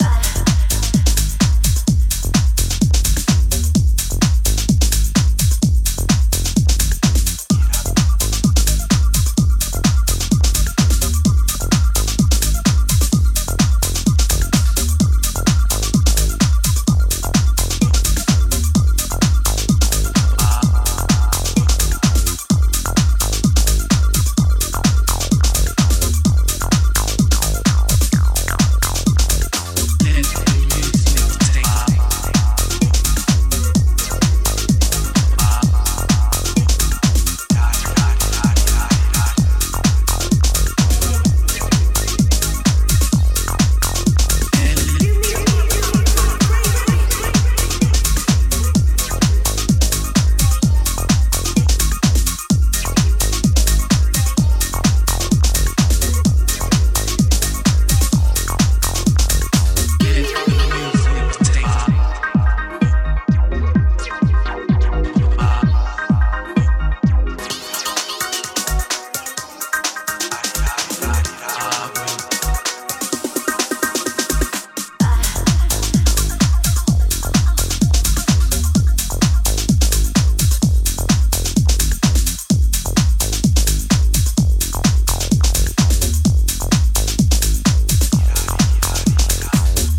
高域の華やかさを意識したテック・ハウス・グルーヴァー